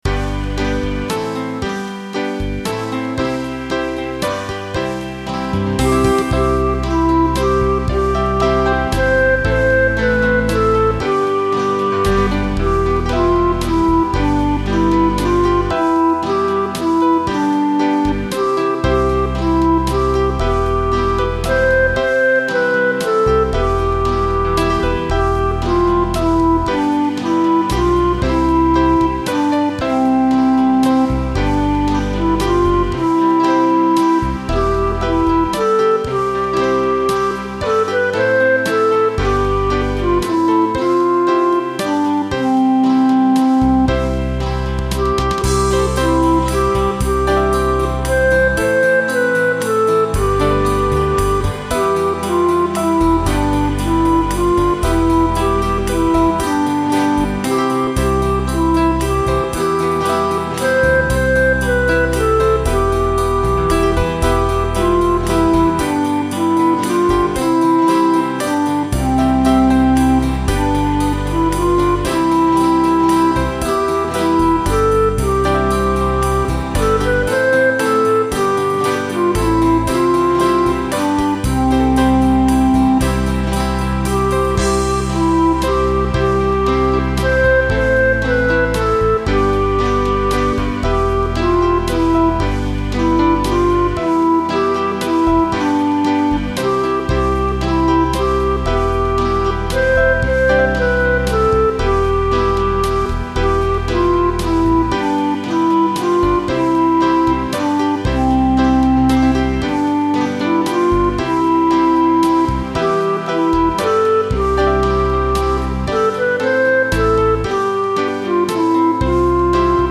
Hail Queen of Heaven | Church Music
It was written by Father John Lingard and my backing is just a render of the MIDI file.